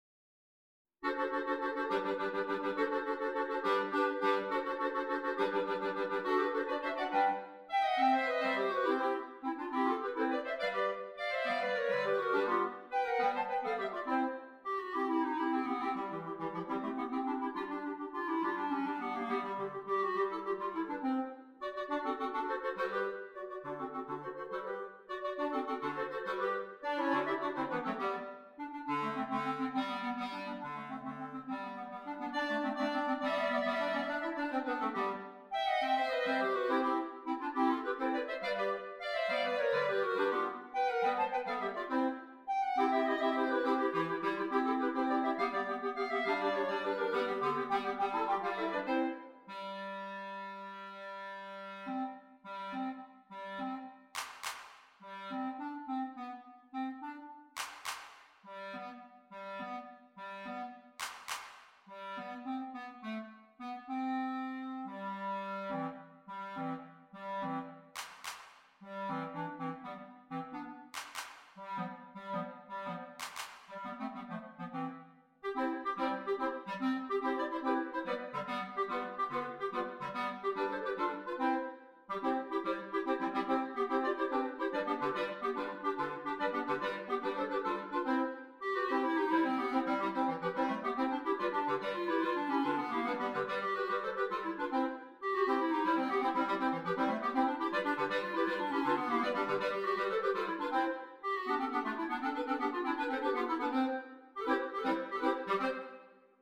4 Clarinets
Traditional Mexican Folk Song